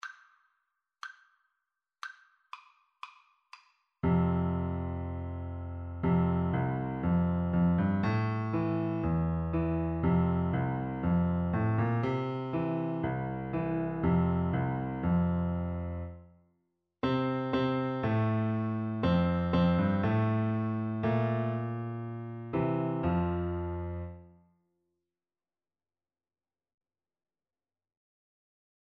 Free Sheet music for Piano Four Hands (Piano Duet)
F major (Sounding Pitch) (View more F major Music for Piano Duet )
Steadily =c.120